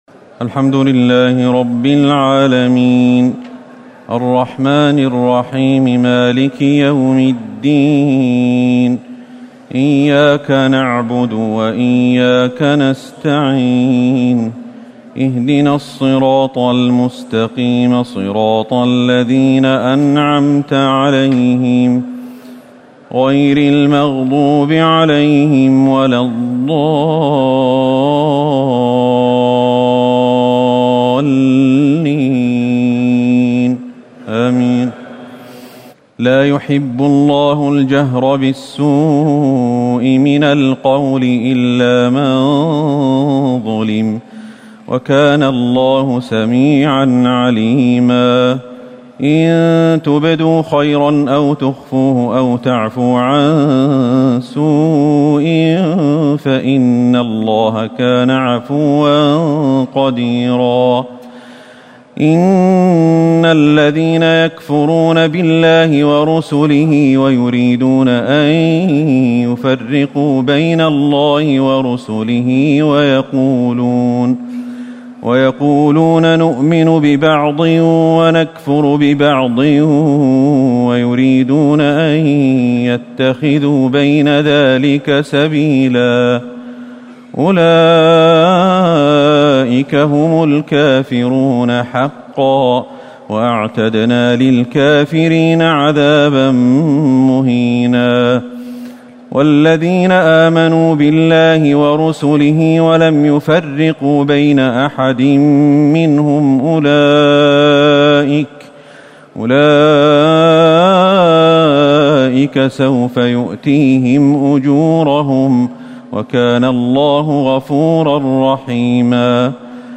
تهجد ليلة 26 رمضان 1439هـ من سورتي النساء (148-176) و المائدة (1-40) Tahajjud 26 st night Ramadan 1439H from Surah An-Nisaa and AlMa'idah > تراويح الحرم النبوي عام 1439 🕌 > التراويح - تلاوات الحرمين